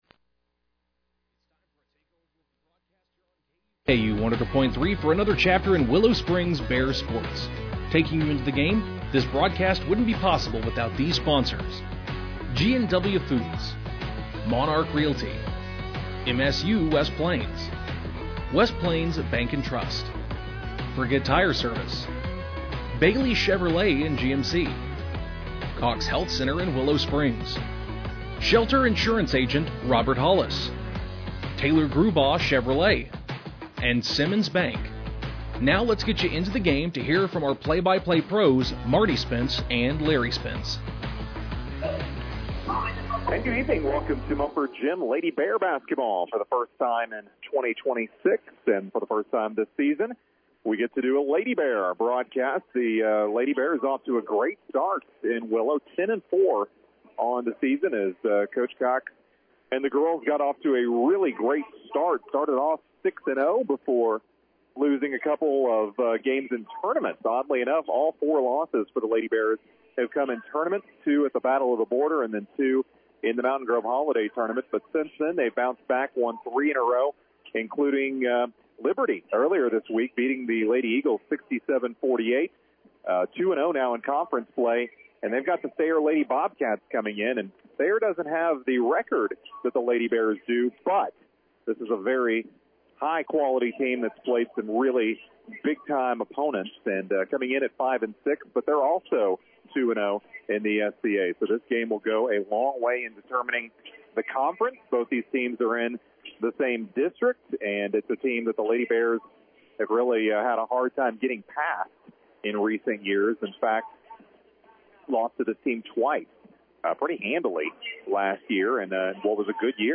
Game Audio Below: Willow won the tip and immediately opened up with their shooting game scoring a 3 on the first possession.